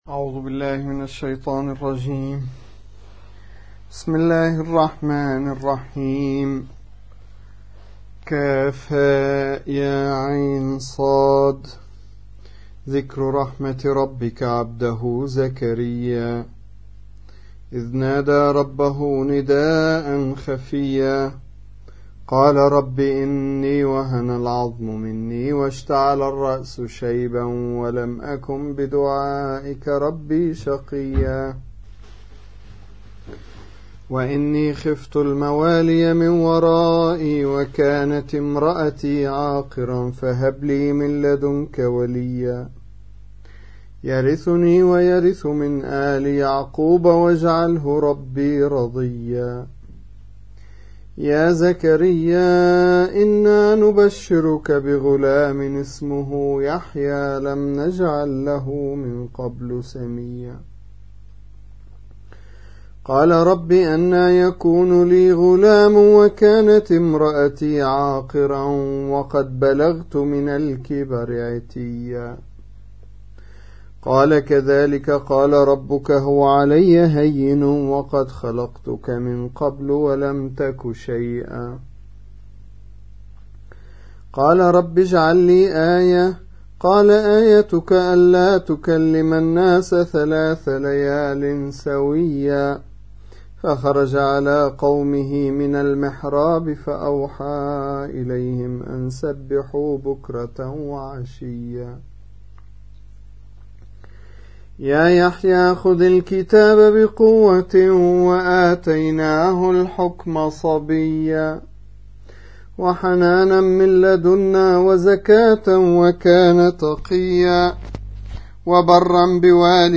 19. سورة مريم / القارئ